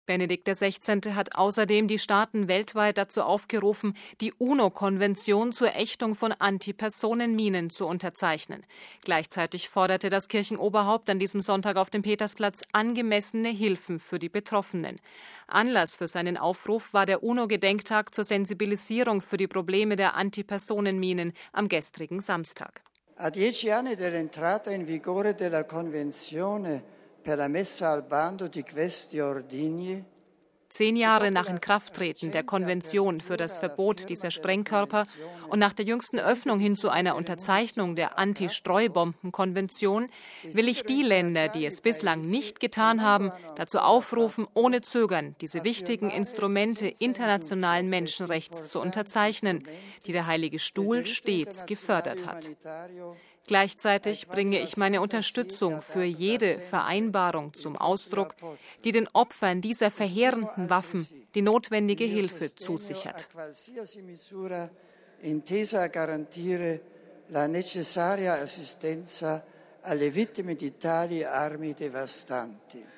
Gleichzeitig forderte das Kirchenoberhaupt an diesem Sonntag auf dem Petersplatz angemessene Hilfen für die Betroffenen. Anlass für seinen Aufruf war der UNO-Gedenktag zur Sensibilisierung für die Probleme der Antipersonenminen vergangenen Samstag.